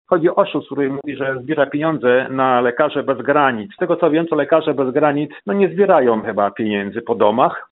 słuchacz Twojego radia